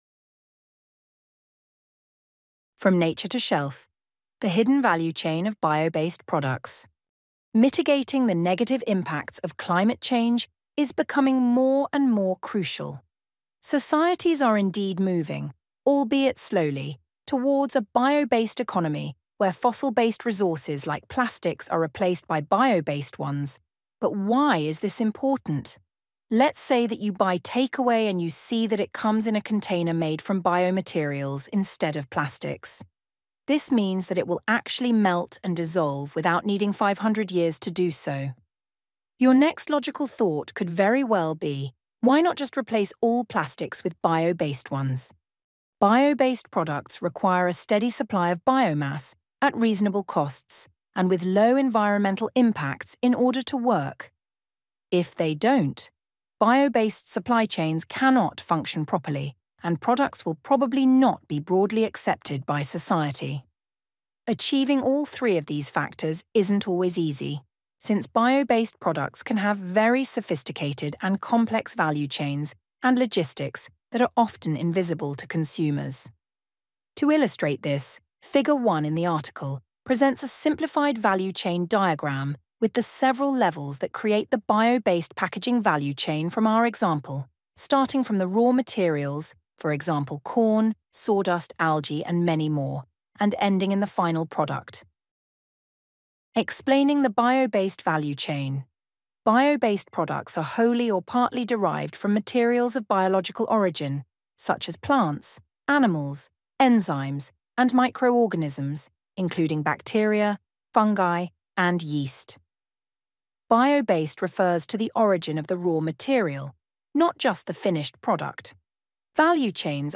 For your convenience, this blogpost is also available as an audio version.
Voice-over-of-the-hidden-value-chain-of-bio‑based-products_Blog_post.mp3